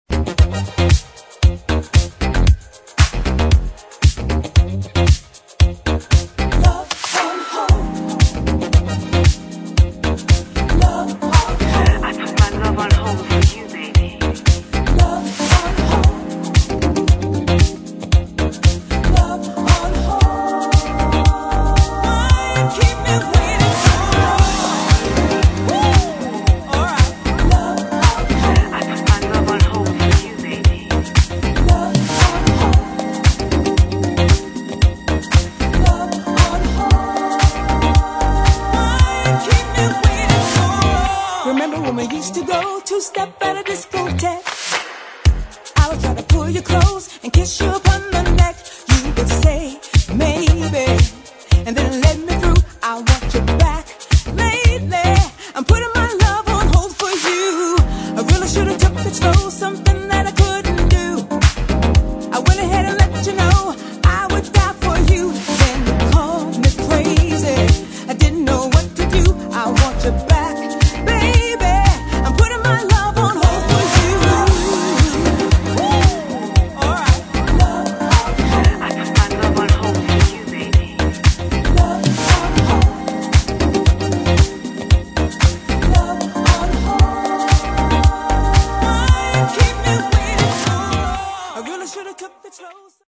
[ DISCO ]
Extended Mix